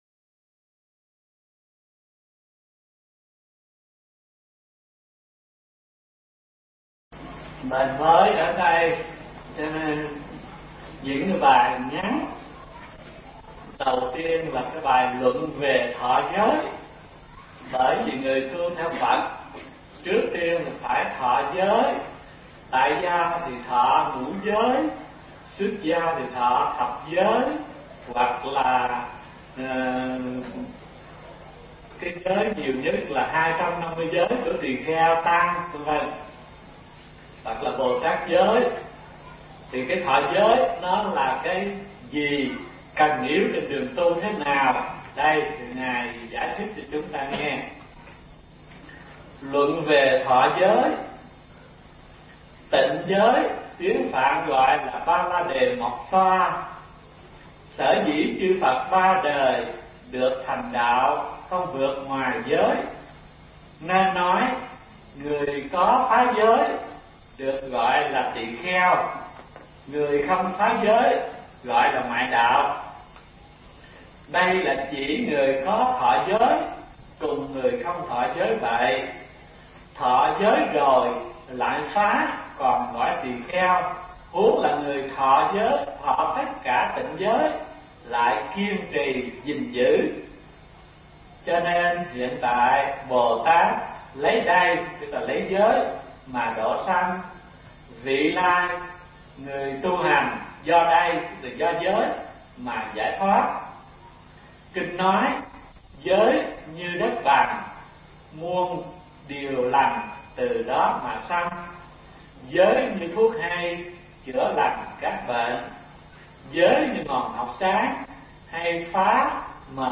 Mp3 Pháp Thoại Khóa Hư Lục 07 – Thọ Giới, Toạ Thiền, Giới Định Tuệ – Hòa Thượng Thích Thanh Từ giảng tại thiền viện Trúc Lâm (Đà Lạt) năm 1994